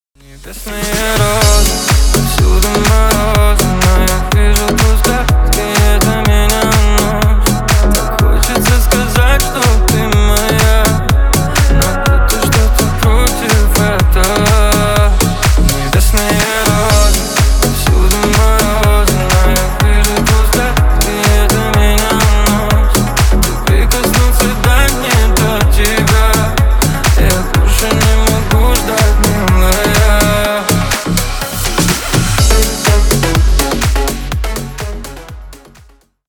• Качество: 320 kbps, Stereo
Ремикс